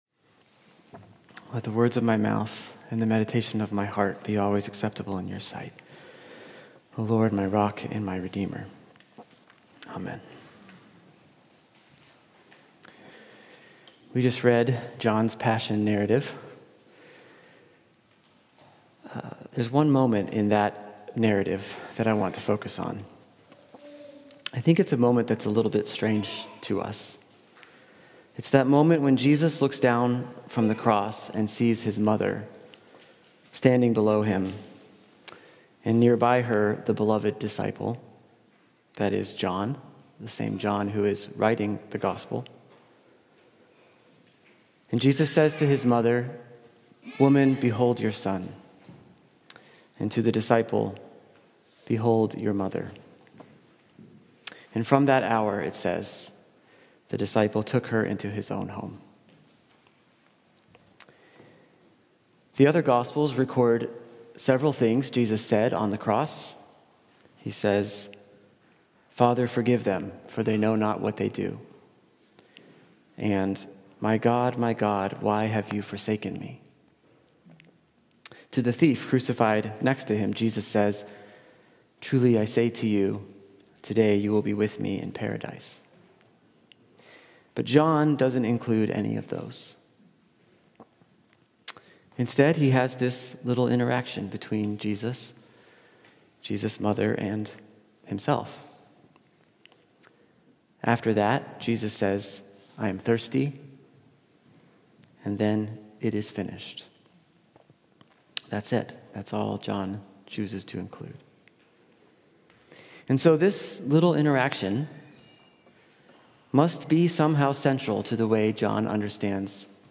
COR Sermon – April 18, 2025